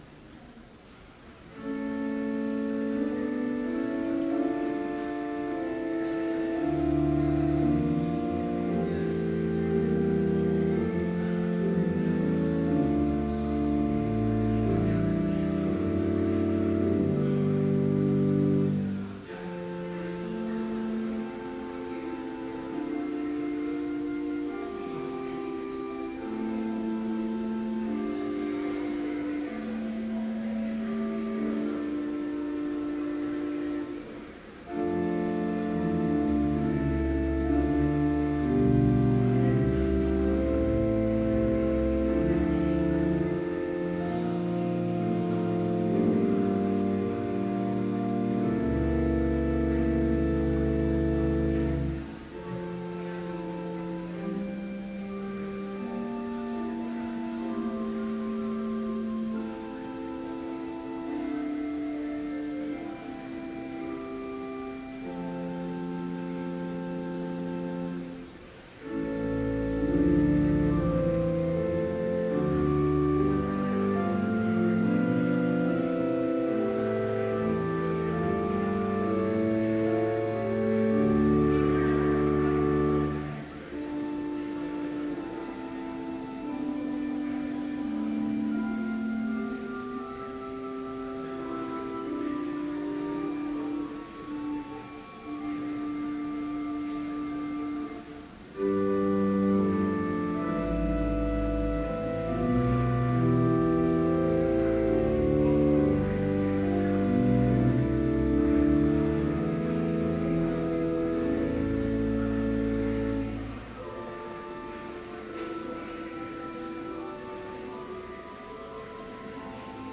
Complete service audio for Chapel - May 9, 2013
We begin by singing v. 1, please stand.